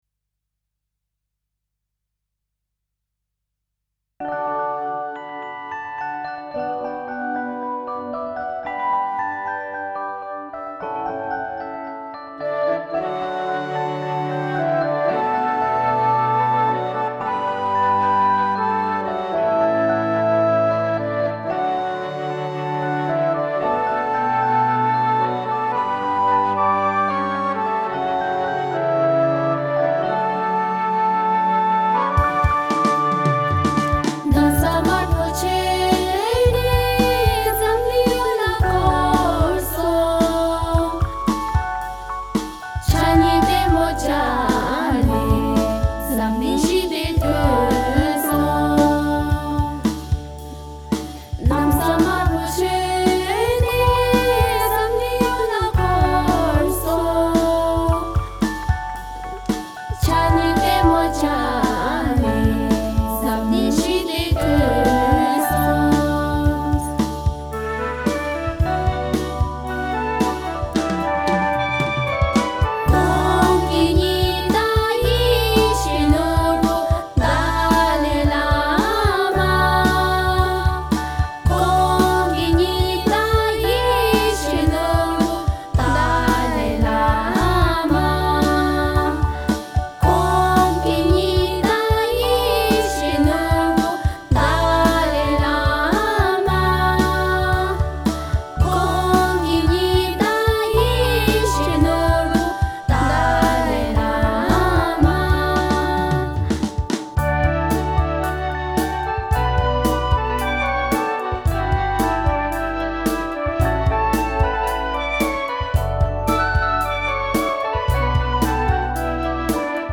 Namsa Marpo: Modern Tibetan Religious Song